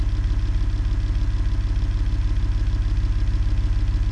rr3-assets/files/.depot/audio/Vehicles/v10_03/v10_03_idle.wav
v10_03_idle.wav